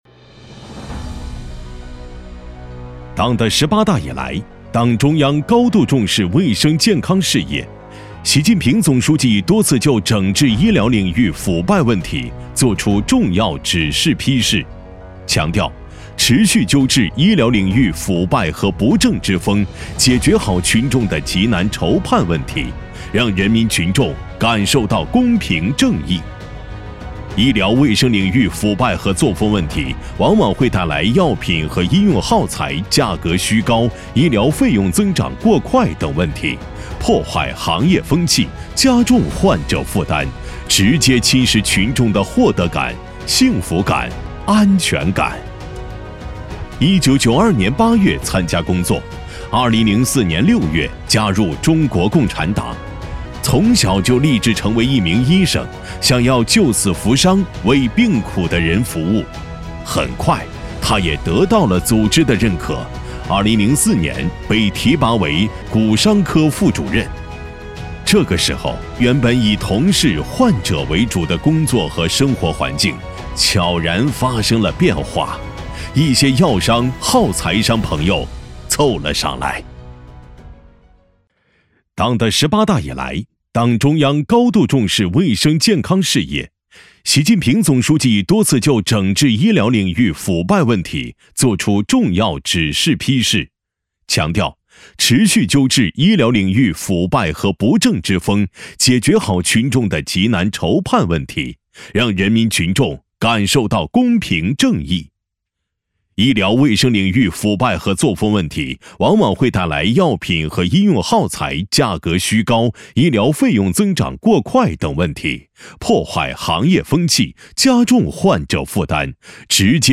医疗专题 配音-配音样音免费在线试听-第1页-深度配音网
男198--专题-医药背后的-黑手-.mp3